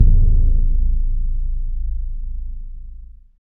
Index of /90_sSampleCDs/Roland LCDP03 Orchestral Perc/PRC_Orch Bs Drum/PRC_Orch BD Roll